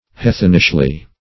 heathenishly - definition of heathenishly - synonyms, pronunciation, spelling from Free Dictionary Search Result for " heathenishly" : The Collaborative International Dictionary of English v.0.48: Heathenishly \Hea"then*ish*ly\, adv. In a heathenish manner.
heathenishly.mp3